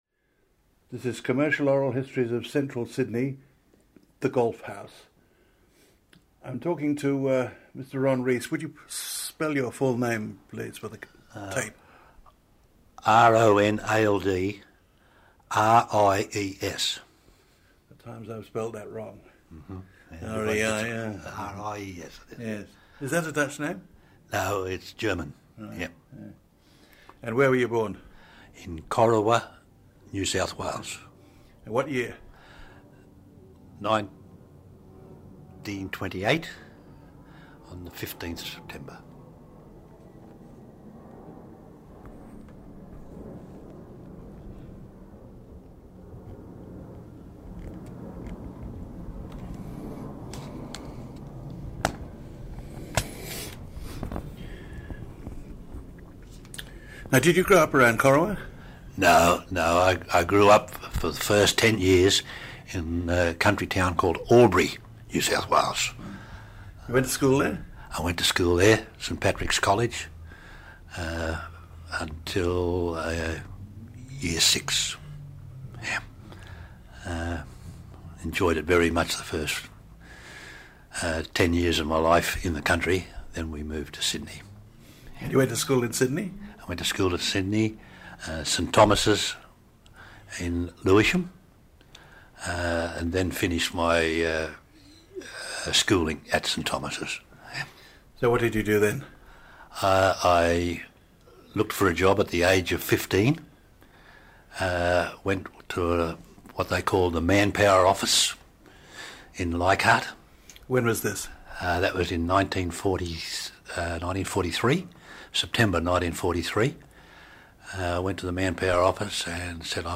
This interview is part of the City of Sydney's oral history theme: Open All Hours